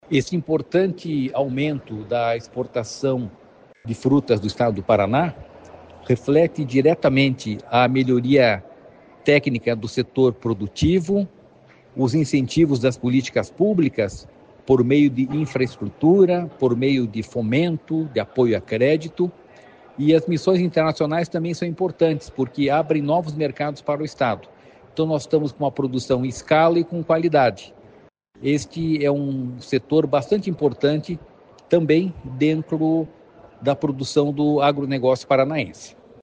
Sonora do diretor-presidente do Ipardes, Jorge Callado, sobre as exportações de frutas do Paraná em 2024